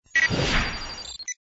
sub target acquired.wav